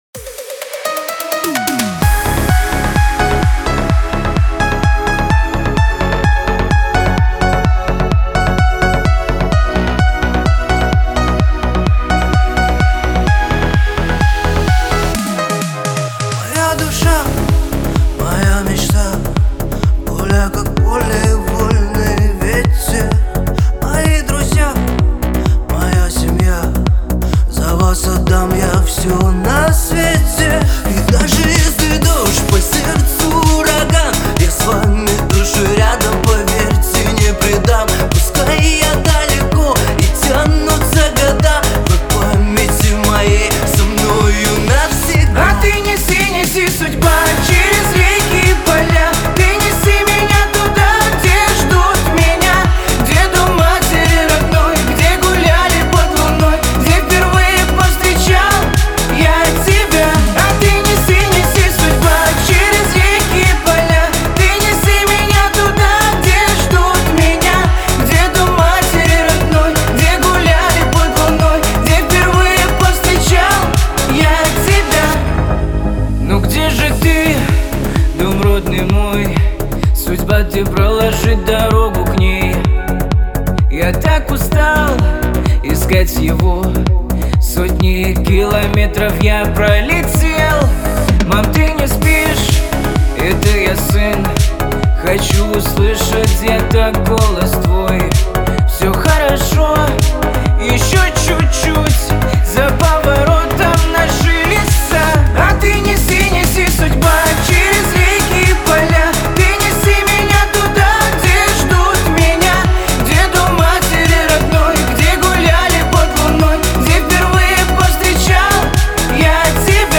Качество: 320 kbps, stereo
Русские поп песни, Поп музыка